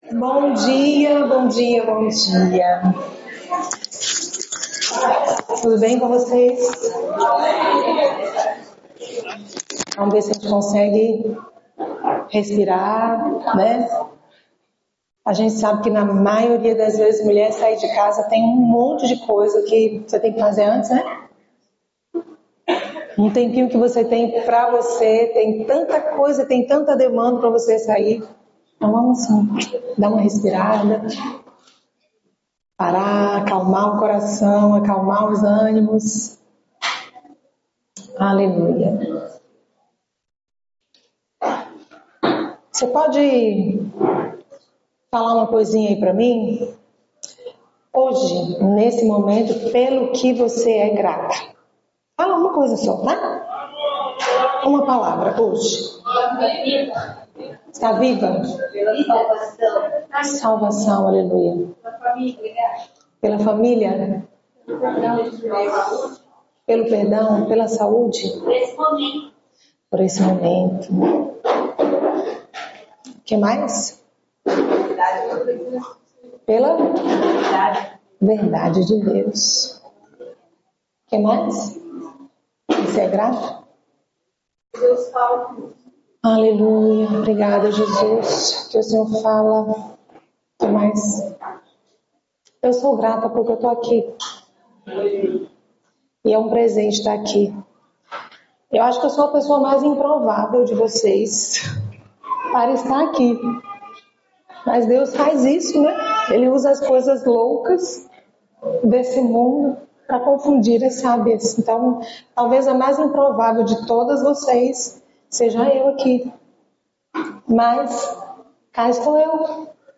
Palavra ministrada às MULHERES no Encontro de Famílias 2025
encontro-de-familias-2025-mulheres.mp3